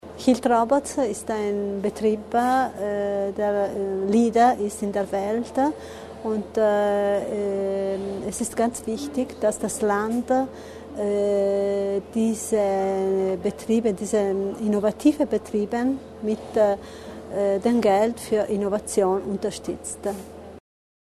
O-Ton von Landesrätin Barbara Repetto